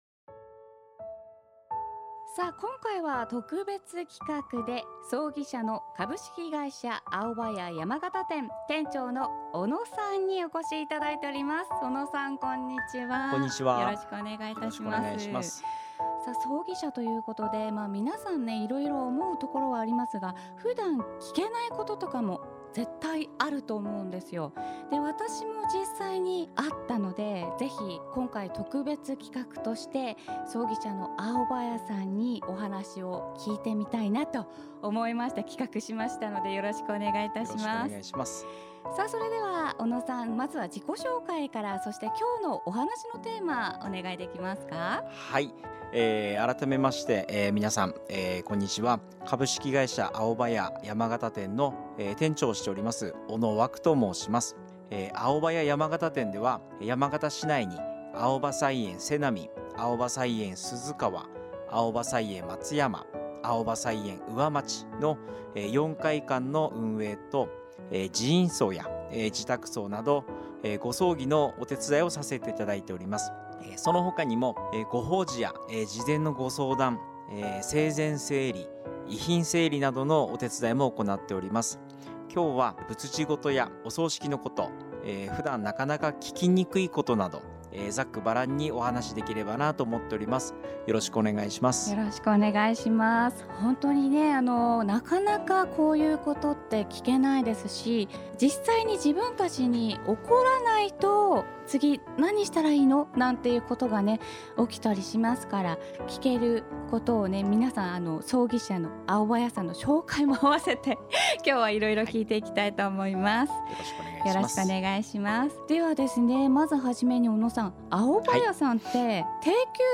内容紹介（番組での主なトーク）